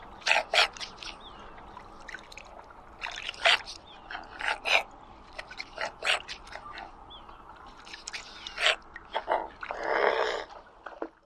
疣鼻天鹅叫声